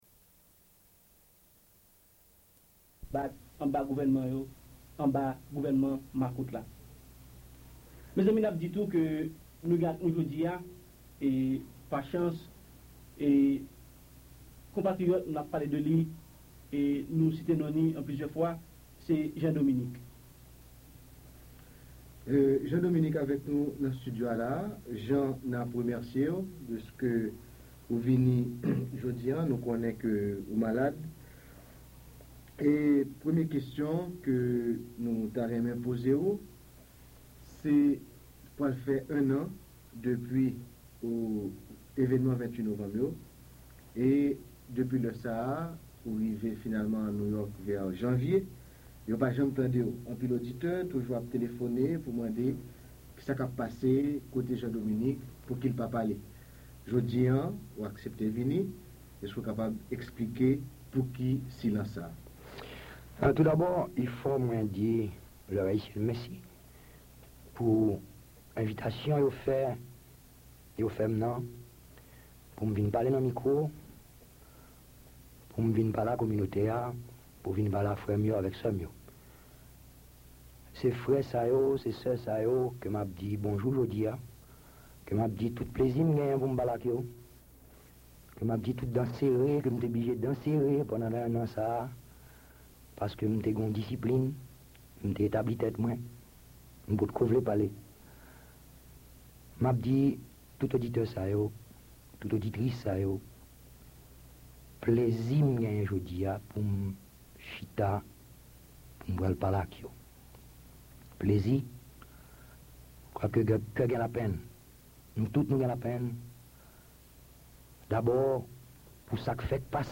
Li pale avèk emosyon sou arestasyon jounalis Radyo Ayiti y
(00:00:00)Jean Dominique is interviewed on L'Heure Haïtienne in New York, one year after the November 28, 1980 press crackdown that forced Radio Haiti into exile.